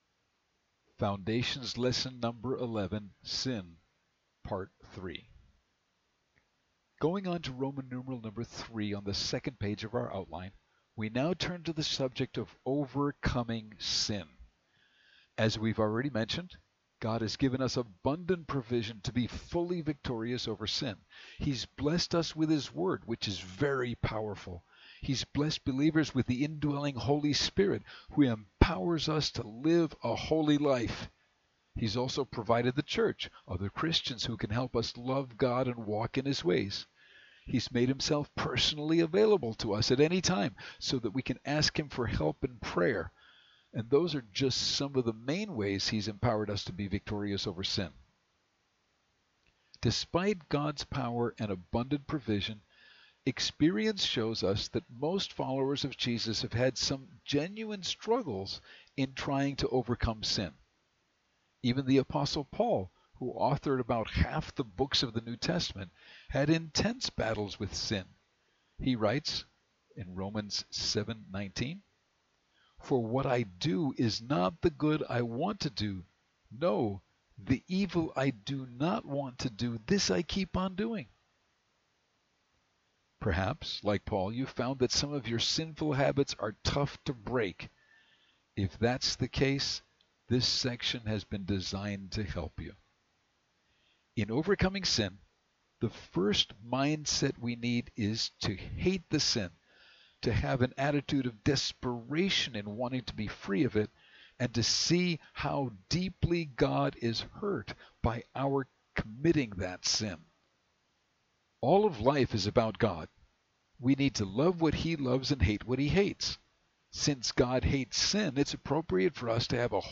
Foundations Lesson #11 - Sin